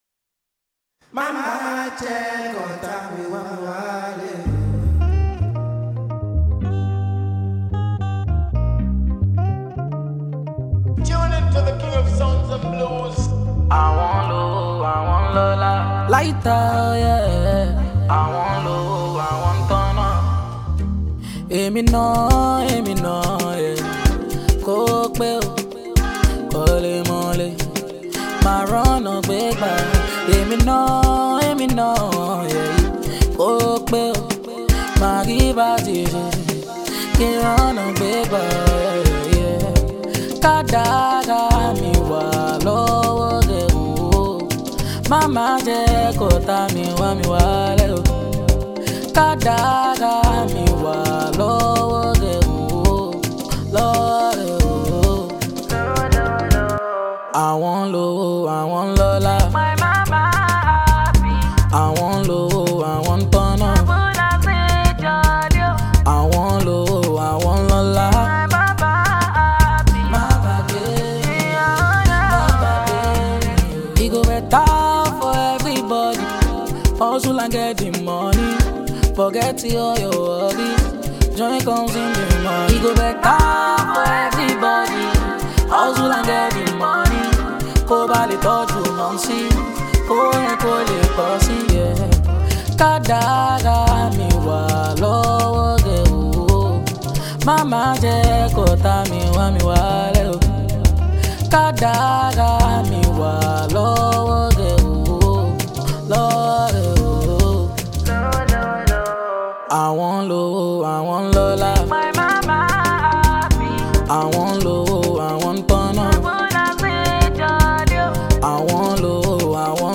Proficient Nigerian electrifying vocalist